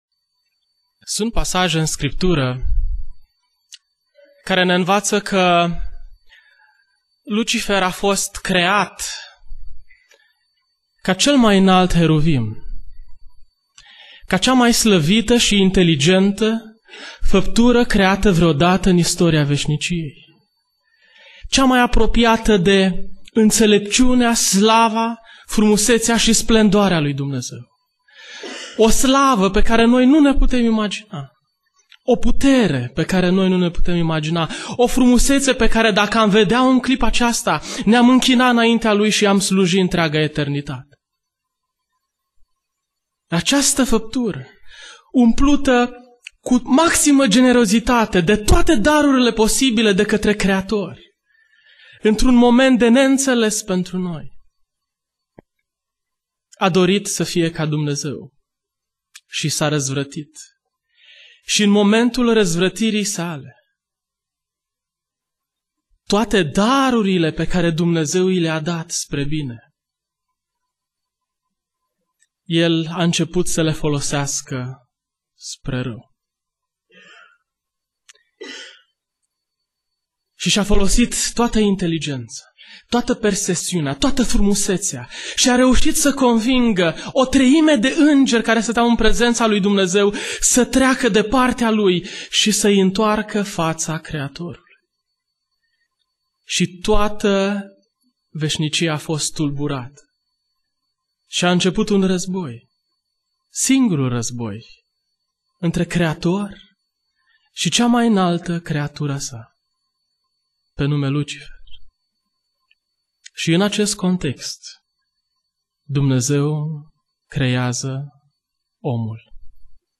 Predica Aplicatie - Matei 4